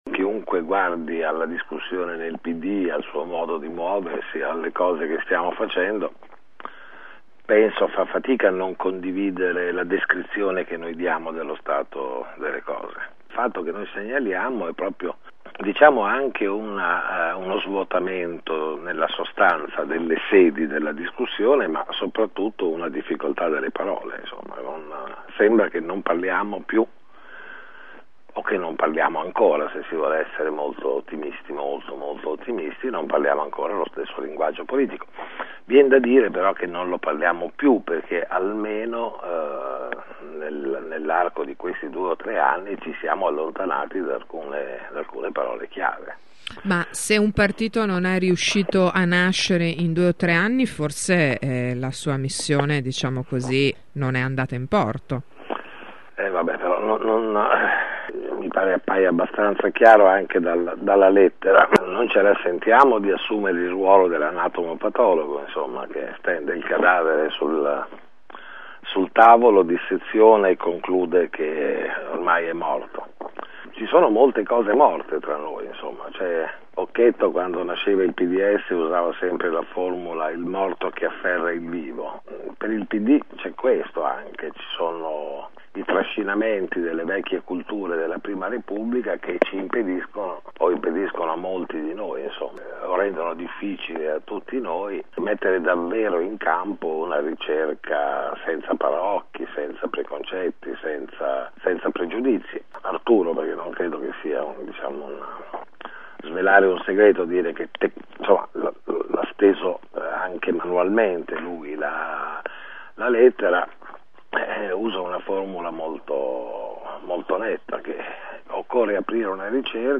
Un partito a “rischio morte” ha detto ai nostri microfoni l’ex presidente della Regione La Forgia.